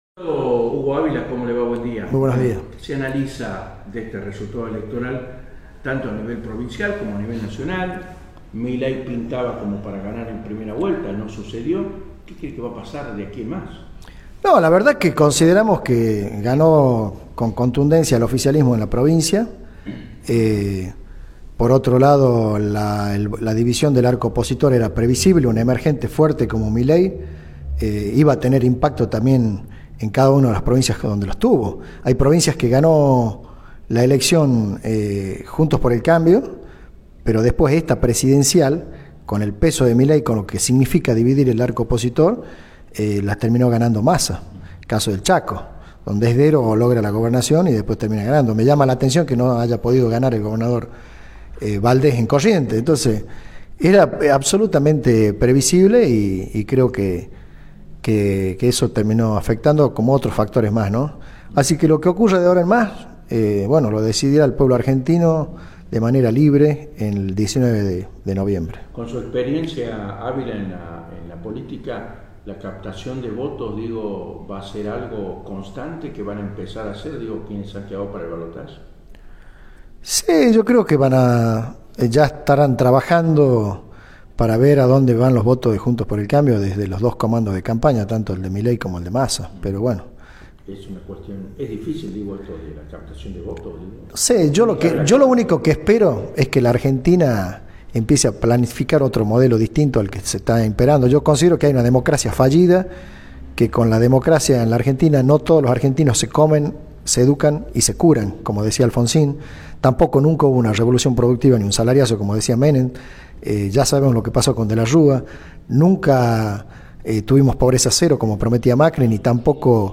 Entrevistas CityRadio CiTy EntrevistasVirales